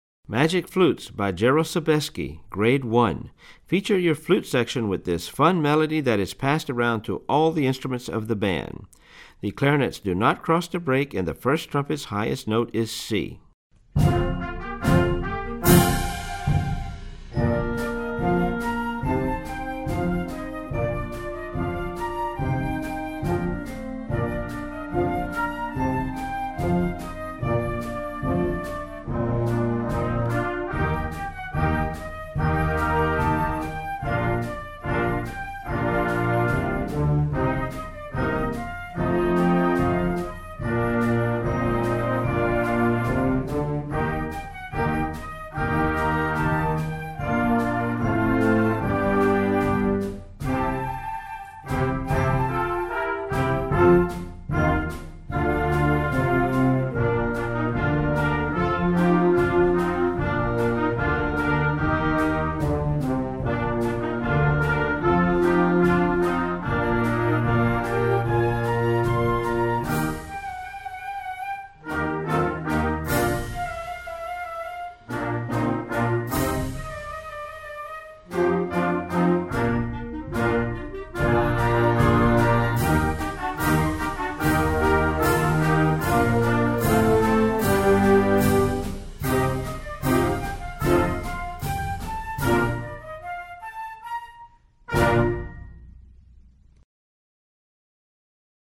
Voicing: Flute Section w/ Band